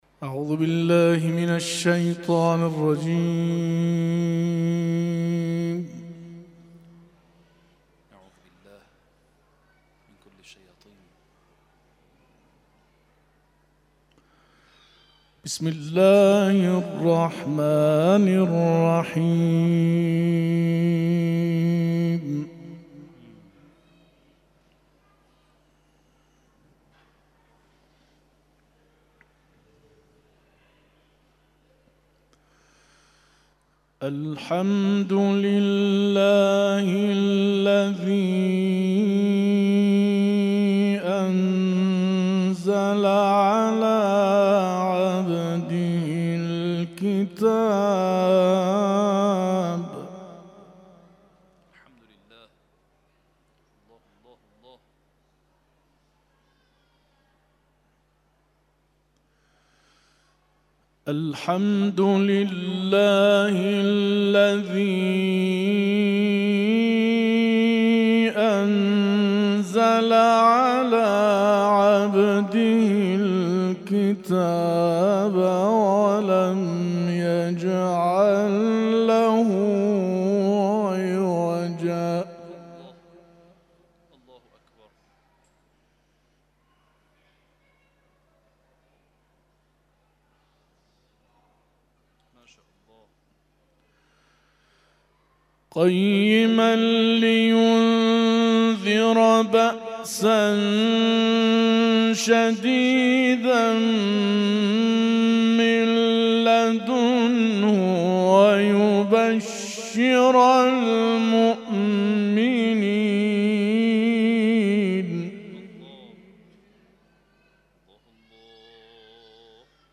در محفل قرآنی آستان عبدالعظیم حسنی(ع) به تلاوت پرداختند.